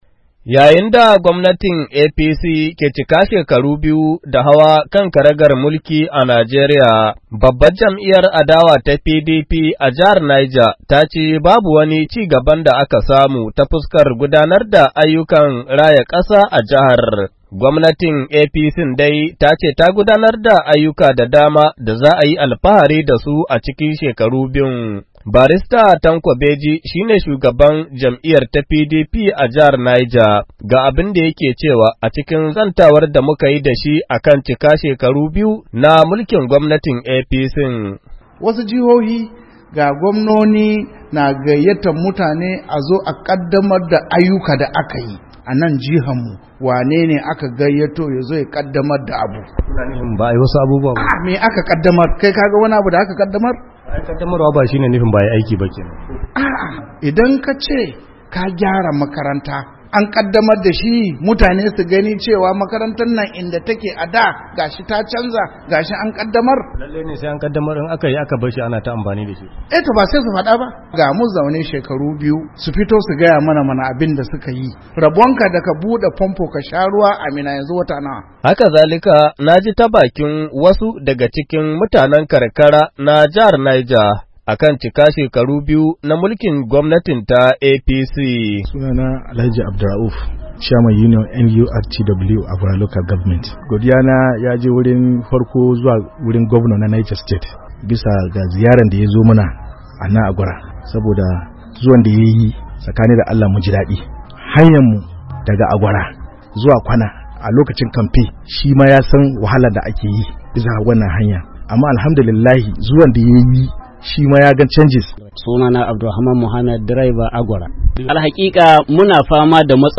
Domin karin bayani ga rahotan